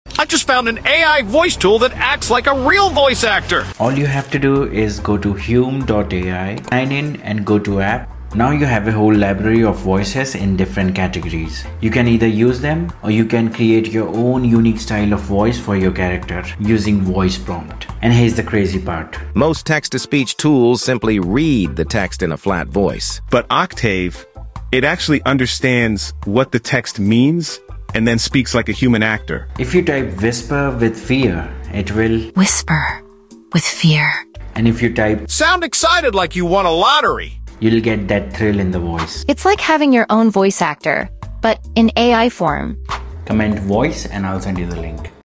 This AI text to speech sound effects free download
This AI text to speech tool sounds HUMAN🔥 it’s Hume AI!